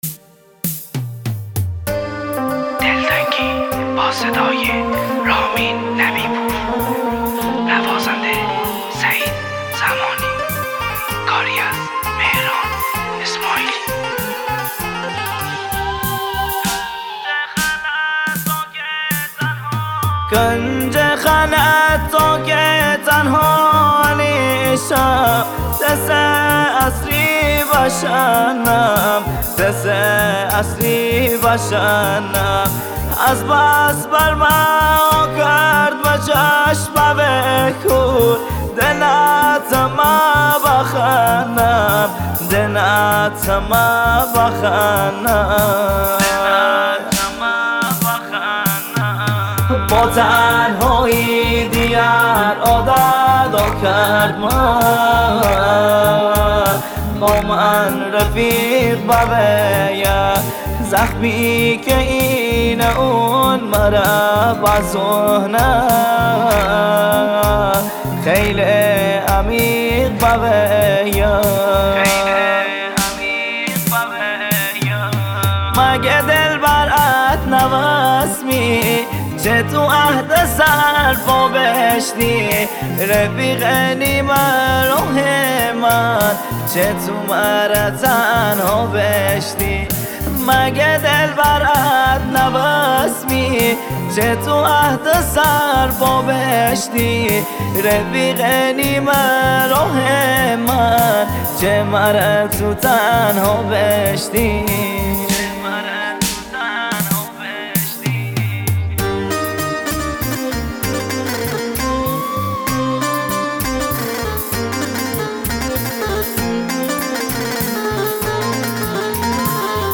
مازندرانی